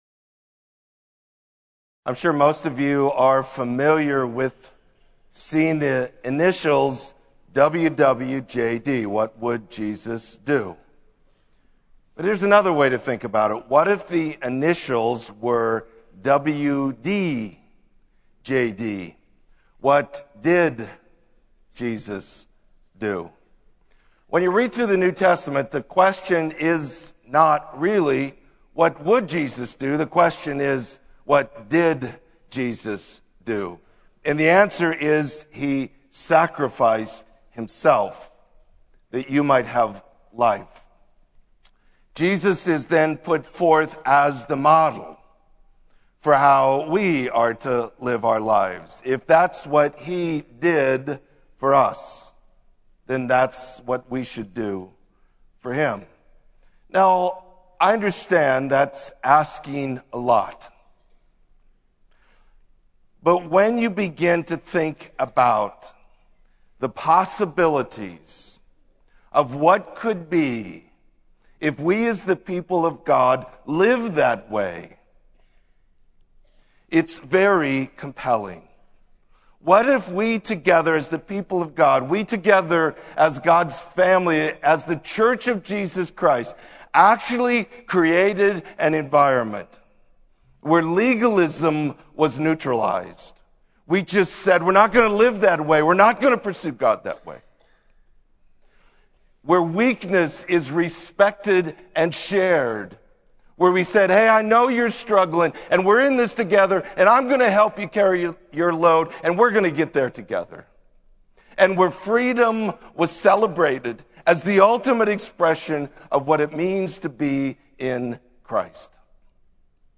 Sermon: Glorify God